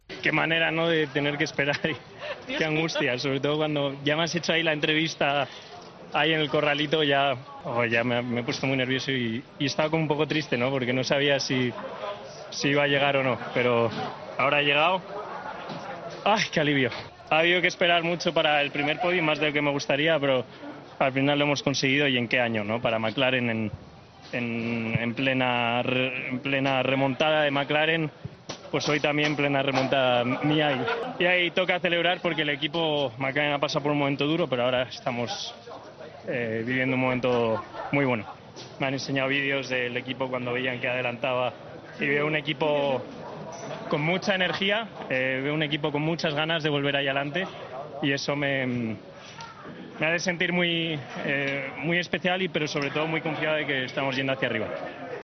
La reacción de Carlos Sainz tras su primer podio en la Fórmula 1